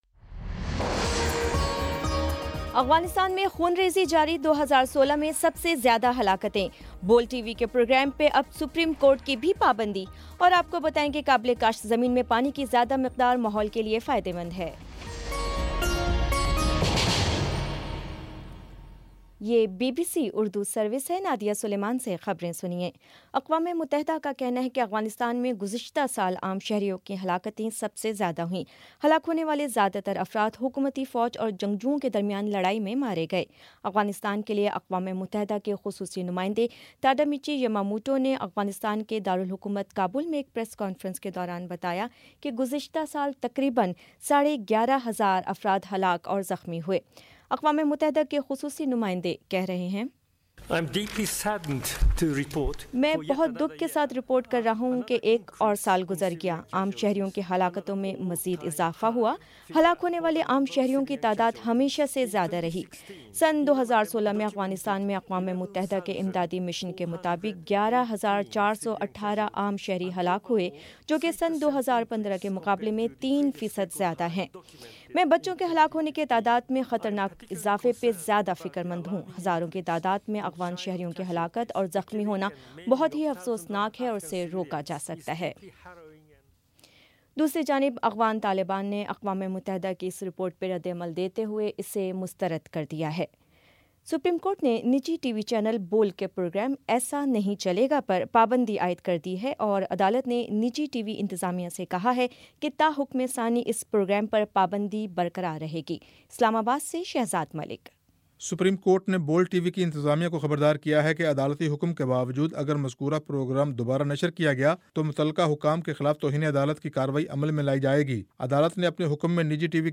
فروری 06 : شام پانچ بجے کا نیوز بُلیٹن